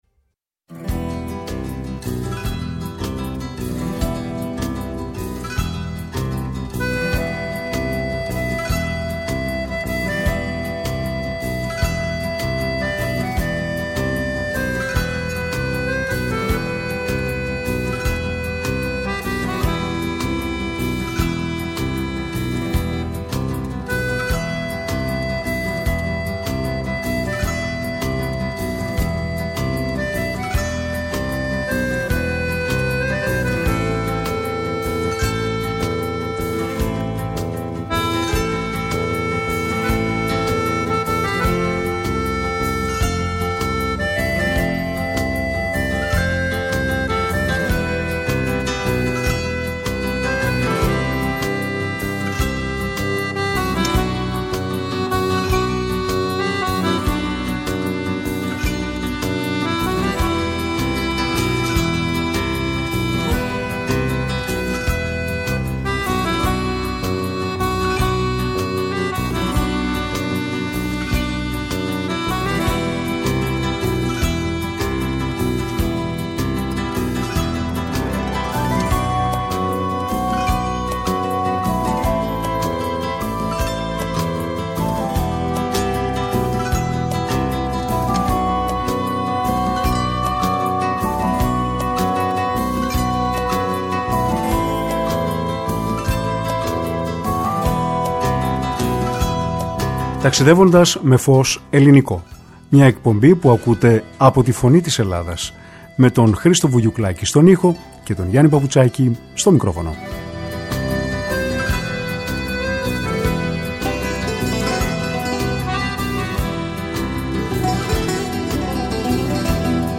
όπου ακούστηκαν τραγούδια της παλιάς Αθήνας.